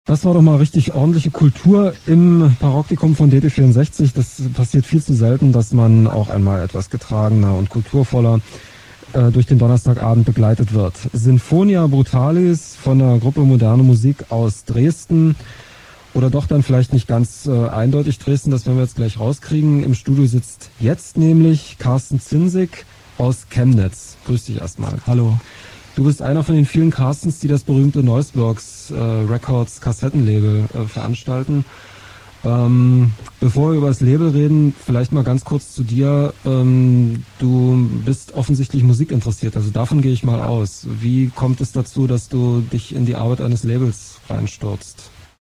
im Radio